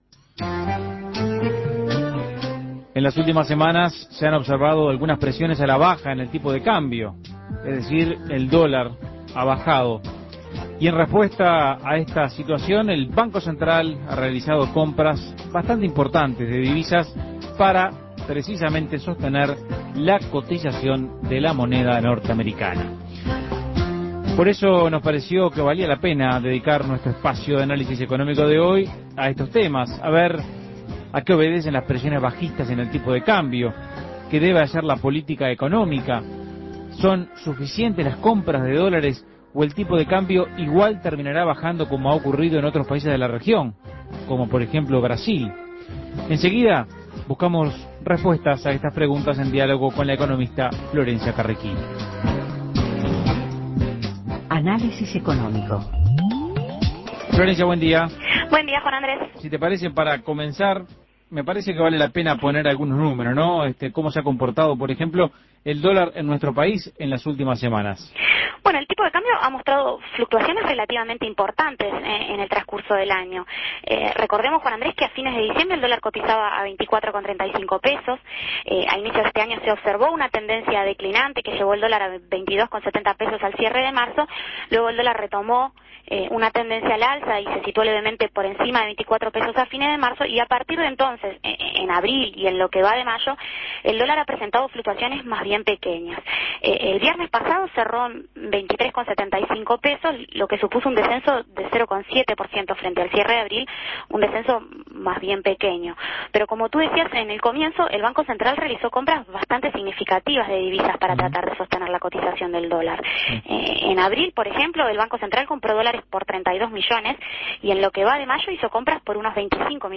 Análisis Económico ¿A qué obedecen las presiones bajistas registradas en el tipo de cambio?